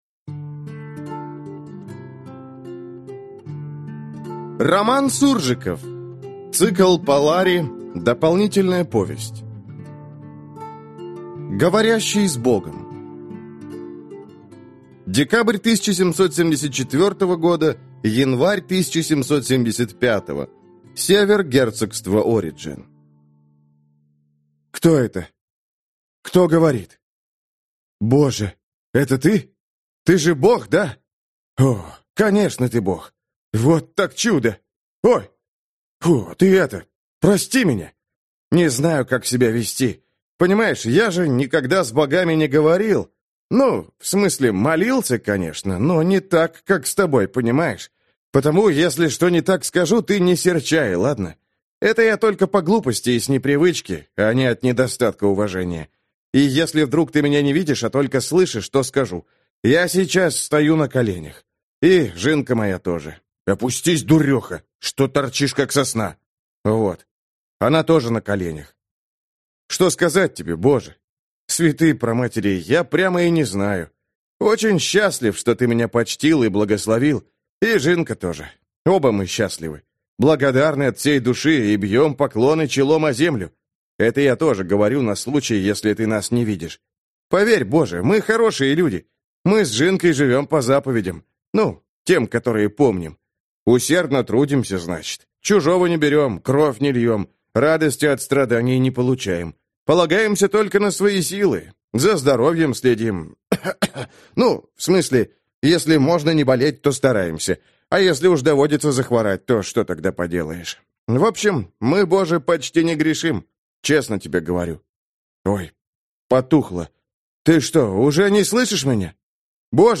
Аудиокнига Говорящий с богом | Библиотека аудиокниг